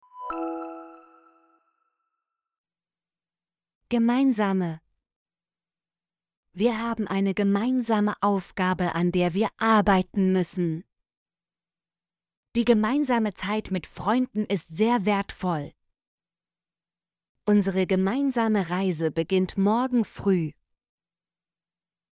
gemeinsame - Example Sentences & Pronunciation, German Frequency List